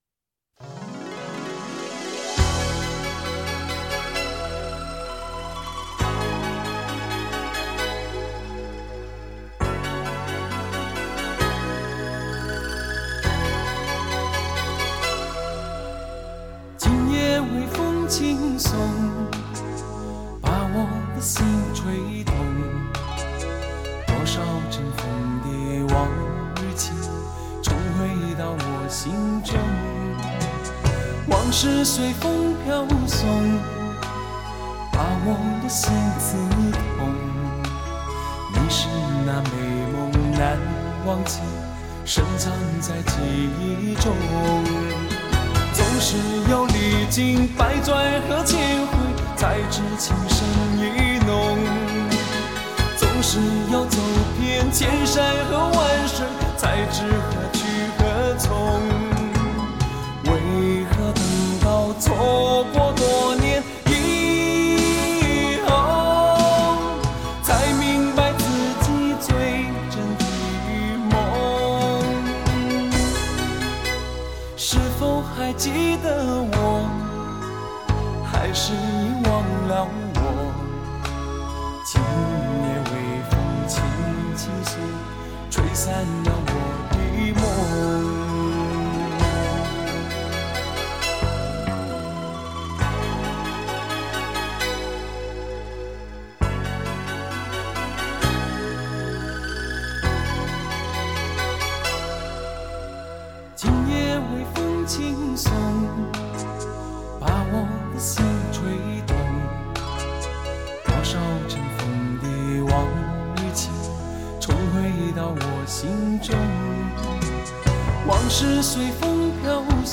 旋律优美动人，朗朗上口，是一首非常经典的老歌